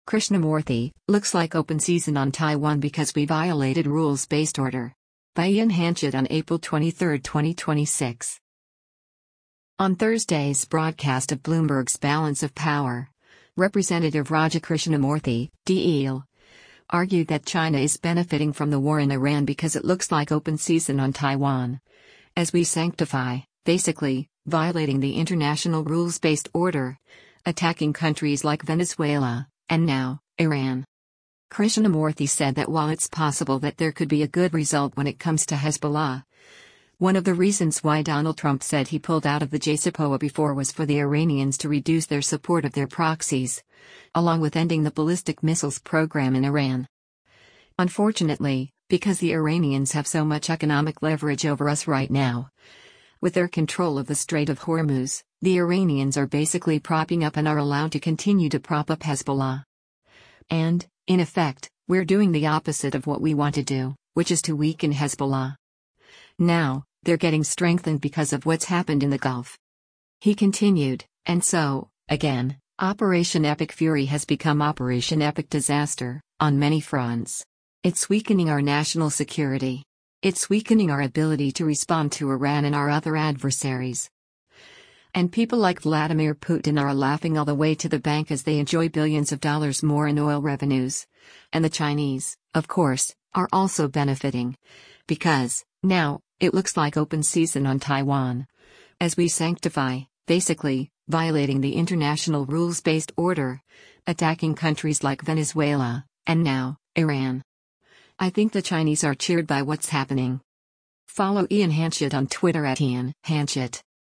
On Thursday’s broadcast of Bloomberg’s “Balance of Power,” Rep. Raja Krishnamoorthi (D-IL) argued that China is benefiting from the war in Iran because “it looks like open season on Taiwan, as we sanctify, basically, violating the international rules-based order, attacking countries like Venezuela, and now, Iran.”